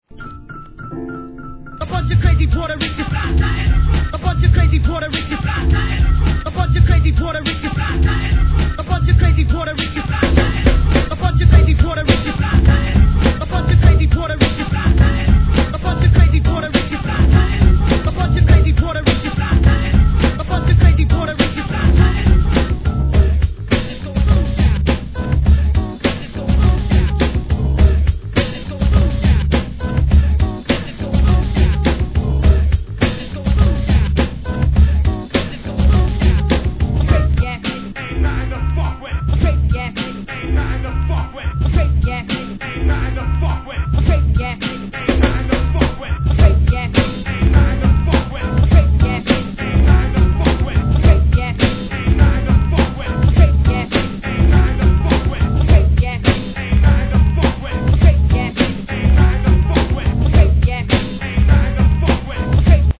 HIP HOP/R&B
1996年、アンダーグランドなトラック物! 大ネタも満載!!